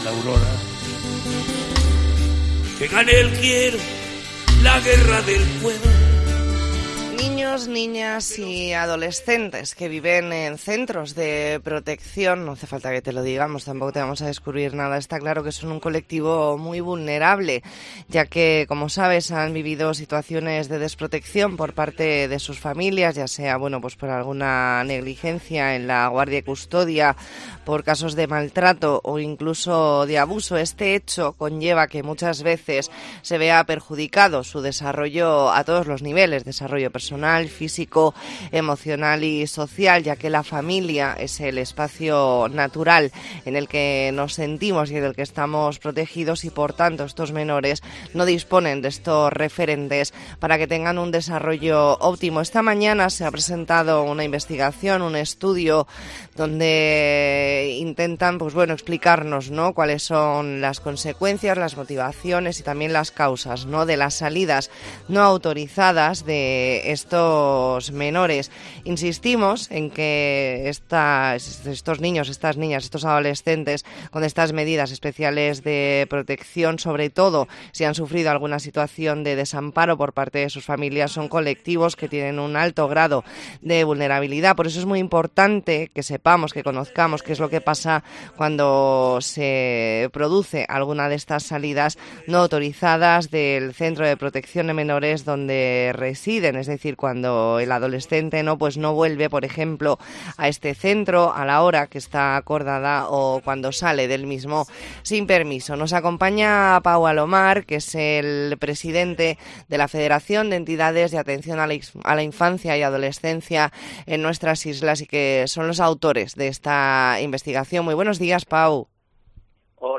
Entrevista en La Mañana en COPE Más Mallorca, miércoles 8 de noviembre de 2023.